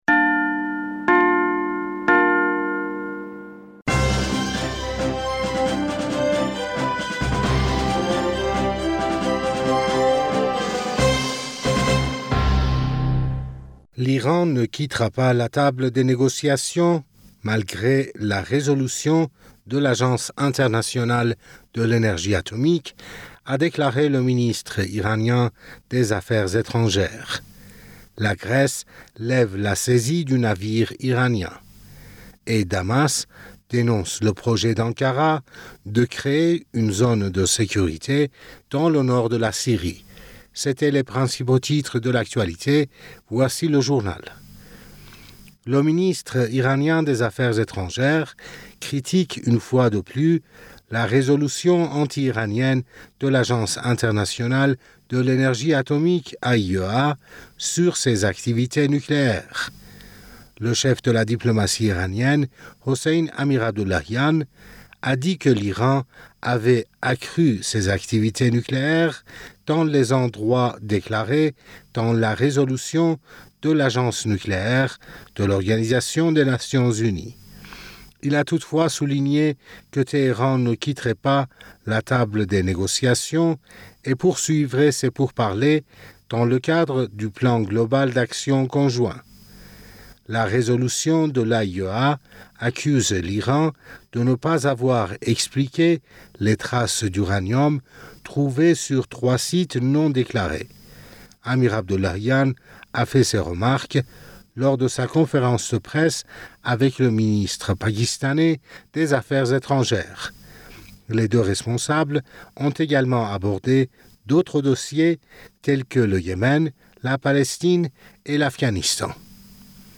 Bulletin d'information Du 14 Juin